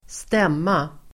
Uttal: [²st'em:a]